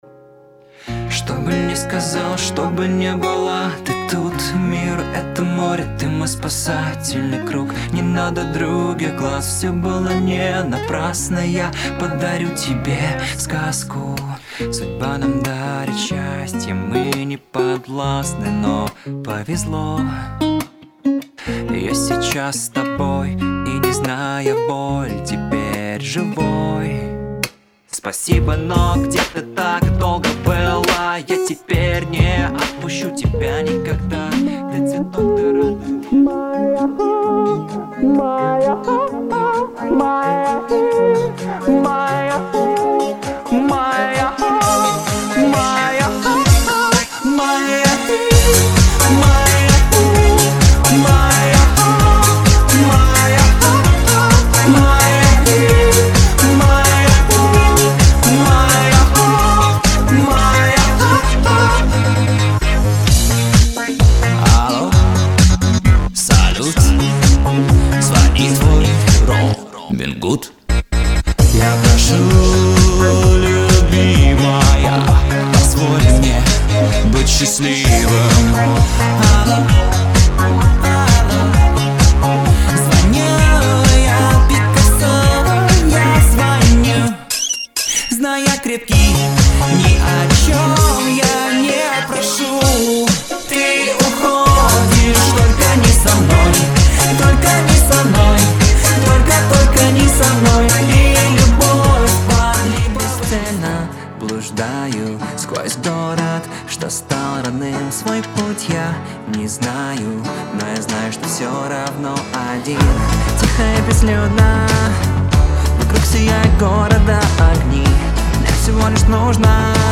Pop
Муж, Вокал/Молодой
Rode NT1-A, focusrite scralett solo 2nd gen, Cubase 11 pro.